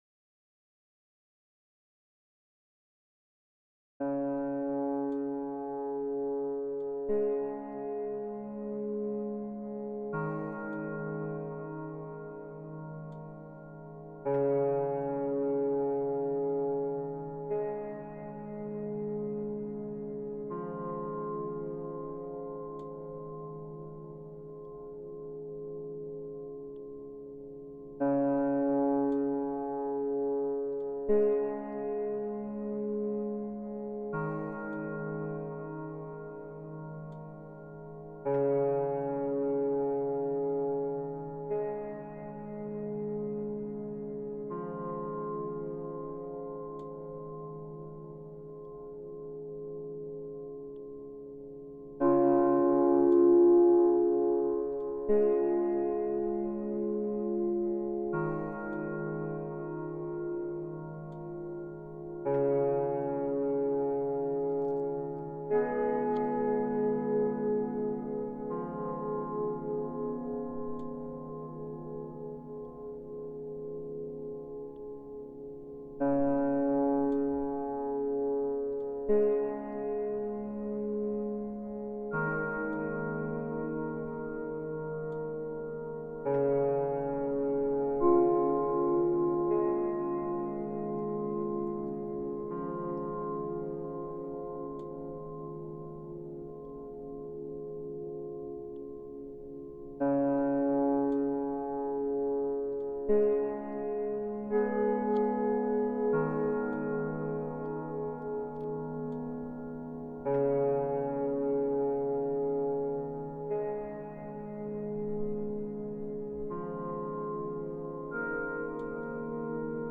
In Sympathy Music for CymaPhone A portfolio of sympathetic resonance compositions enlightened by the mystical forms of rāga and sound healing.